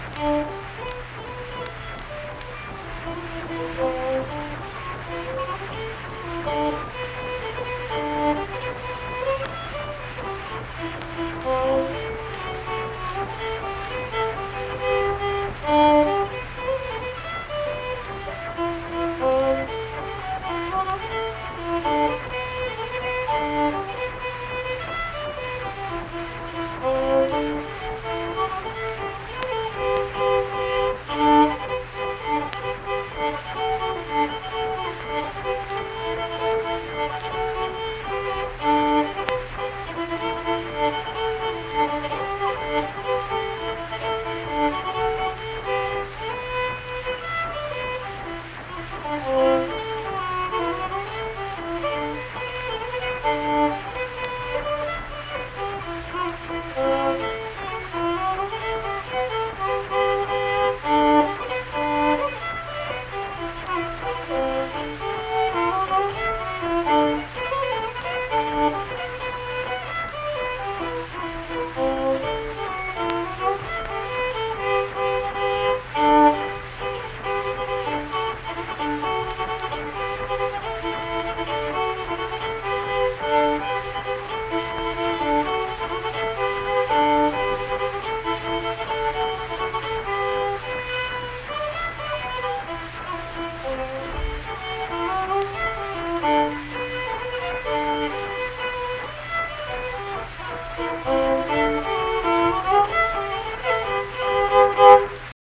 Unaccompanied fiddle music performance
Instrumental Music
Fiddle
Harrison County (W. Va.)